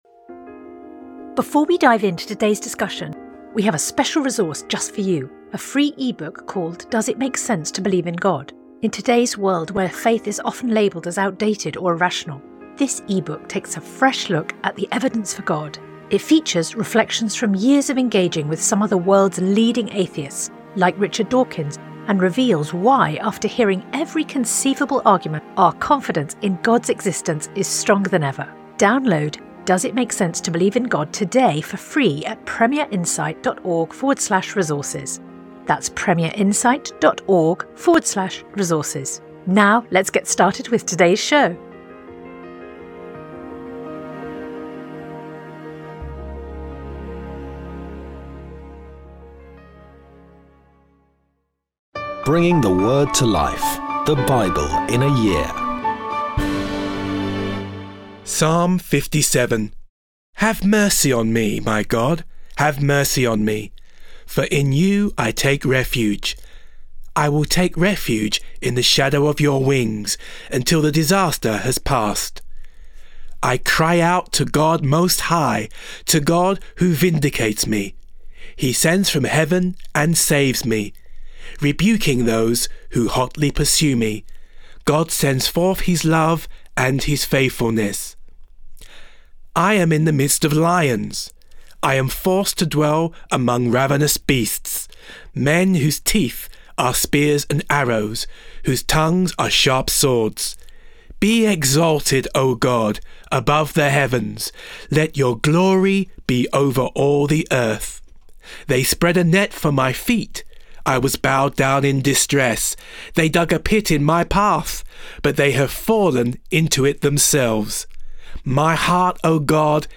Today's readings come from Psalms 57; 1 Samuel 27-29; Psalms 31